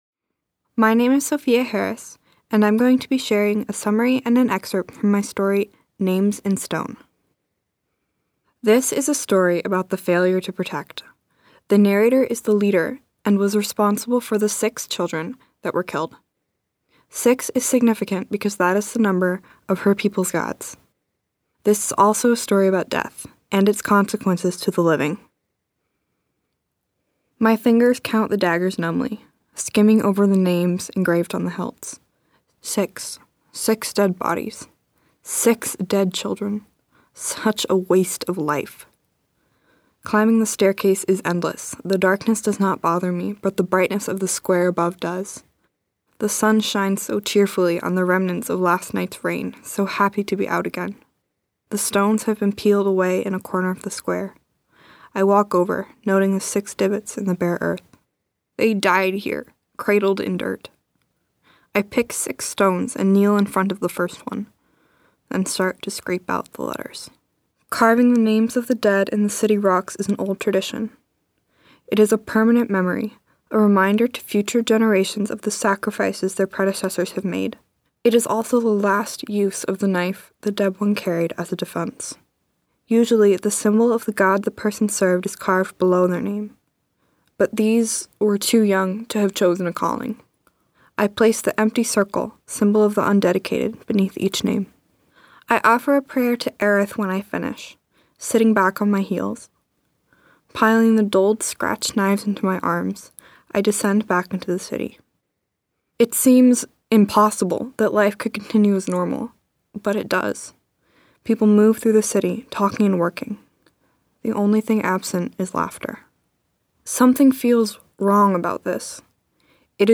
This year, MoPop and Jack Straw Cultural Center collaborated to provide winners of their annual Write Out of This World Writing Contest with a unique experience: Winners participated in a writing workshop, voice workshop, and recording session at Jack Straw, resulting in a professional recording of each writer reading their work.